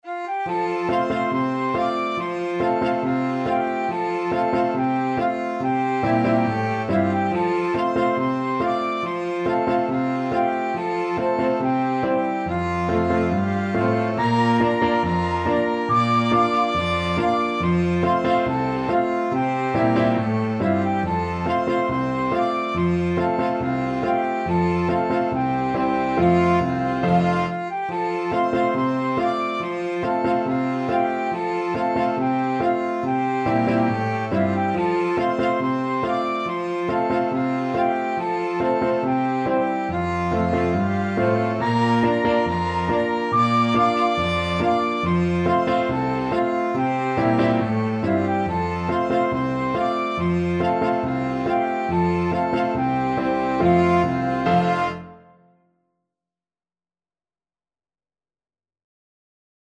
Flute
FluteViolin
Clarinet
Piano
A traditional song from the US.
F major (Sounding Pitch) (View more F major Music for Flexible Ensemble and Piano - 3 Players and Piano )
4/4 (View more 4/4 Music)
= 140 Molto Allegro (View more music marked Allegro)
Traditional (View more Traditional Flexible Ensemble and Piano - 3 Players and Piano Music)